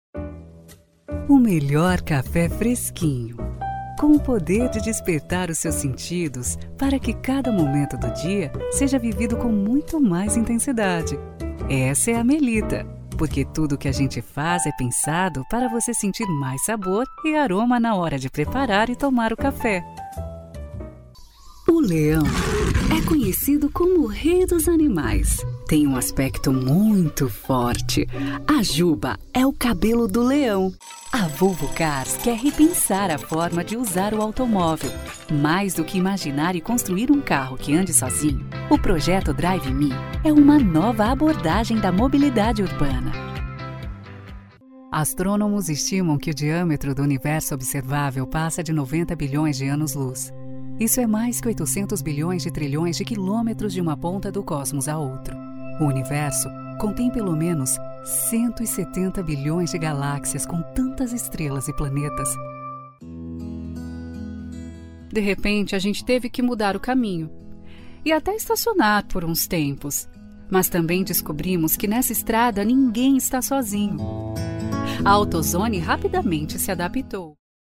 16 years of experience, my interpretation is smooth and pleasant, with a captivating melodic style, bringing credibility and adding value to your brand.
Sprechprobe: Sonstiges (Muttersprache):
My interpretation is smooth and pleasant, with a catchy melodic style, bringing credibility and adding value to the brands.